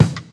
• Clean Kick Drum Single Hit E Key 187.wav
Royality free steel kick drum sample tuned to the E note. Loudest frequency: 504Hz
clean-kick-drum-single-hit-e-key-187-Um6.wav